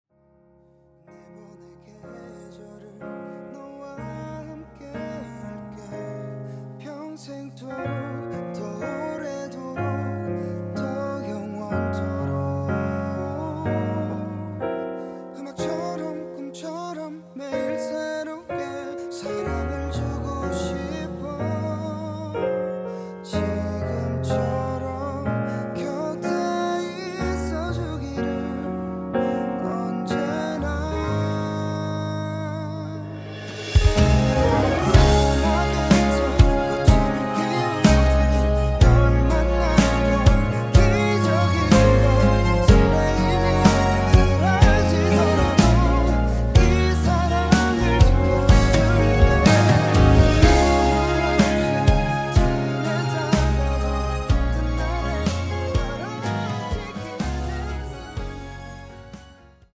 음정 원키 4:19
장르 가요 구분 Voice Cut